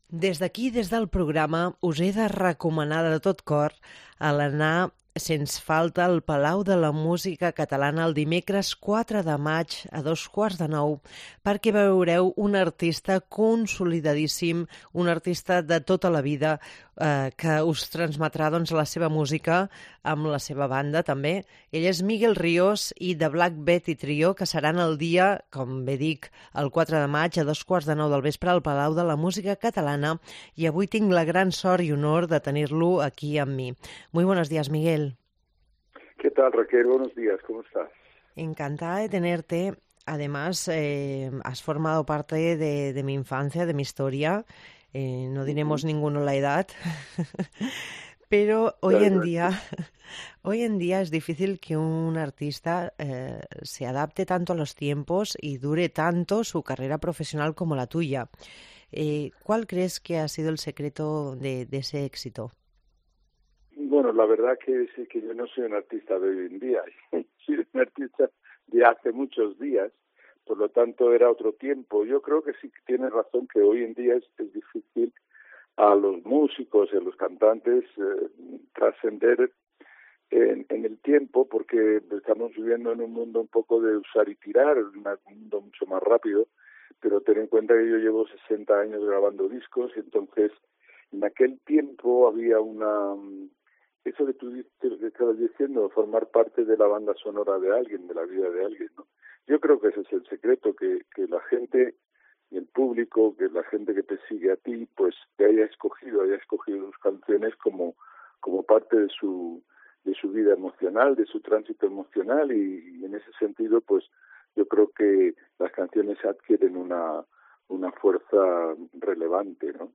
AUDIO: Entrevista Miguel Rios